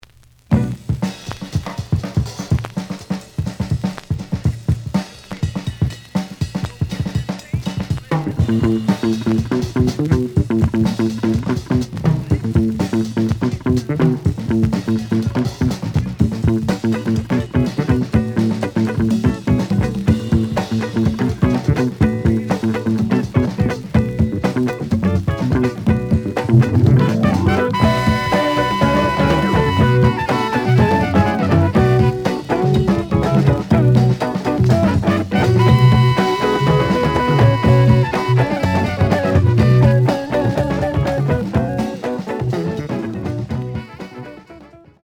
The audio sample is recorded from the actual item.
●Genre: Funk, 70's Funk
Slight noise on both sides.)